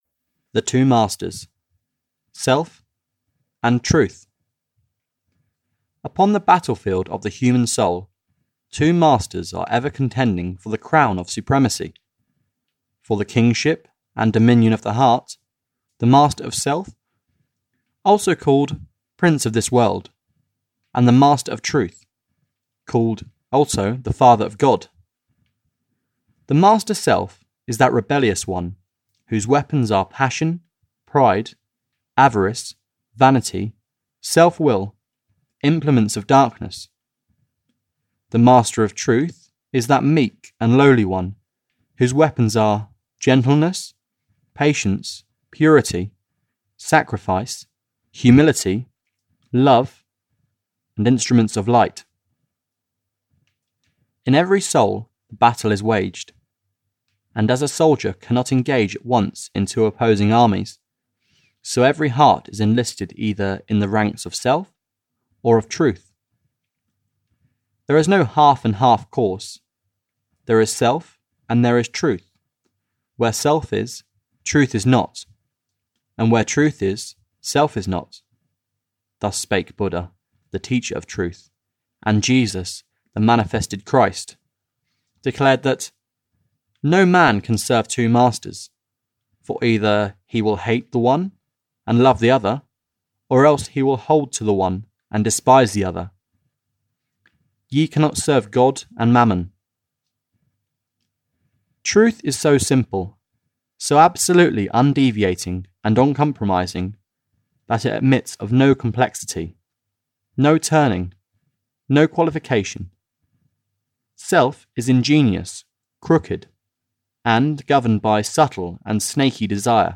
The Way Of Peace (EN) audiokniha
Ukázka z knihy